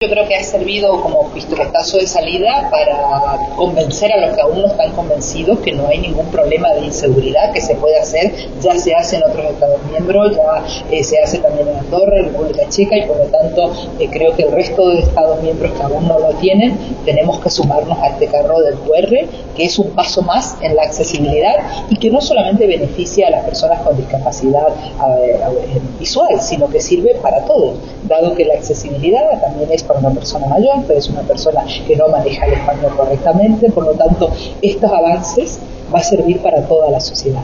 Posteriormente tuvo lugar una mesa redonda en la que intervinieron los eurodiputados